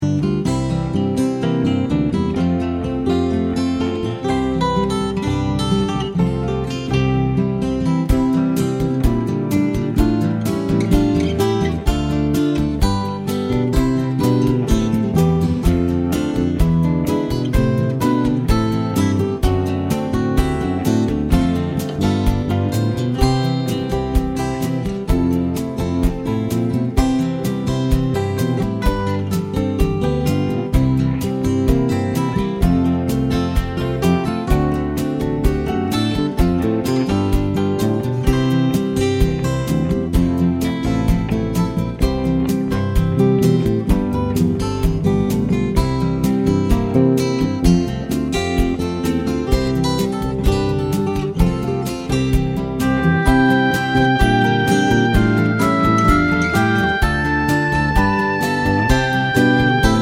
no Backing Vocals Country (Male) 2:51 Buy £1.50